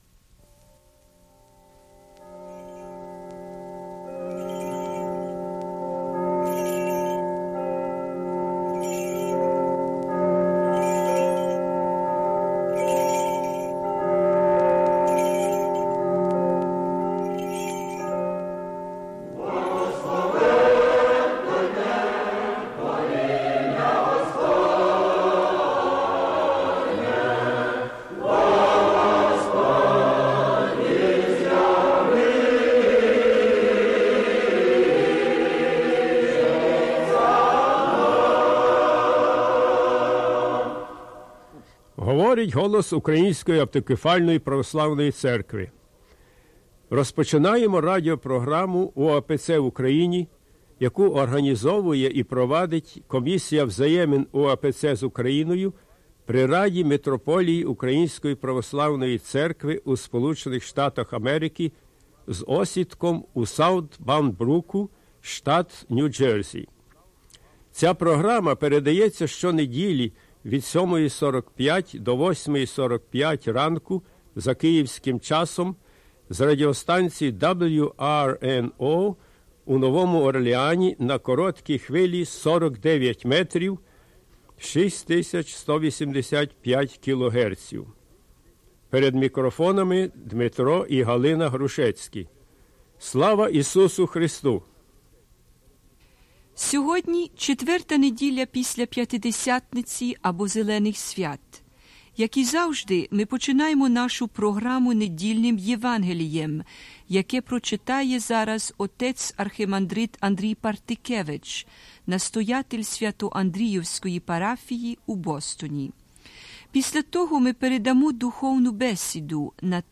Gospel reading and "spiritual discussion"
Choral rendition of "Vichna pamiat"